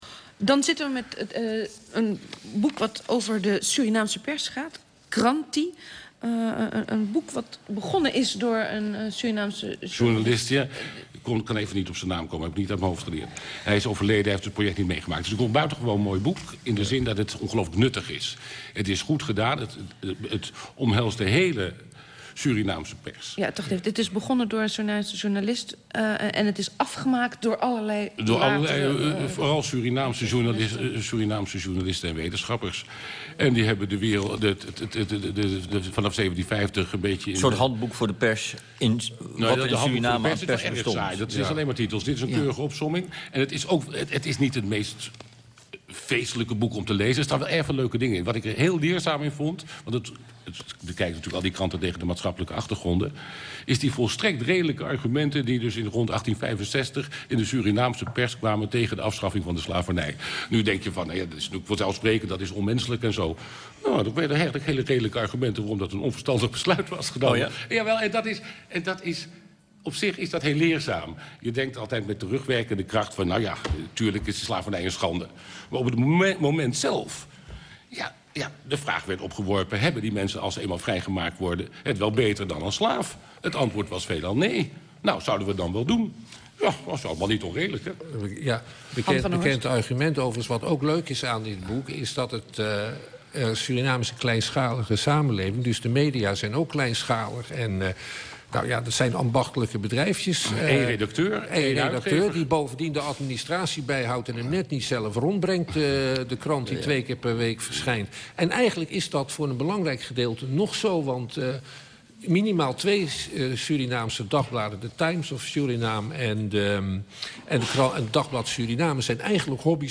Beluister het VPRO radio fragment van OVT op 26 oktober 2008.